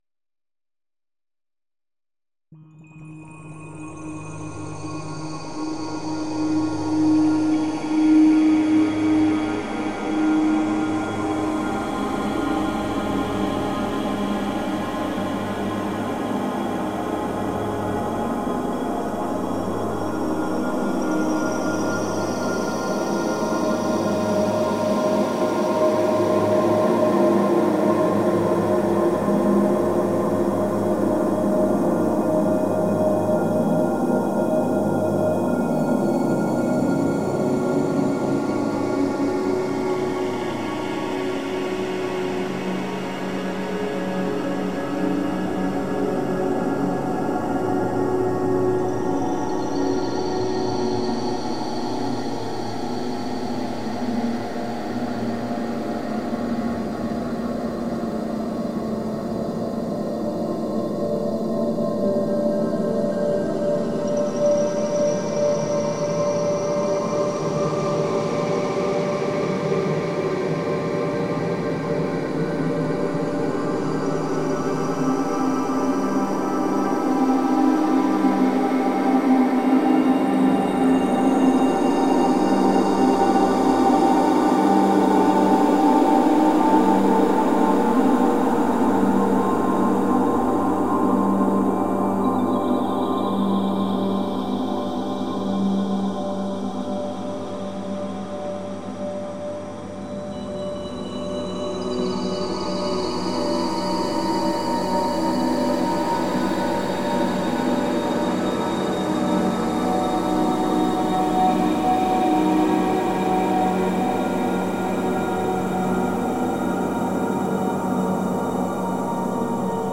I was playing around with the soundscapes and came across an effect called one finger, four effects which had a really good drone quality.
I am not a good keys player by any means but I put together something with this.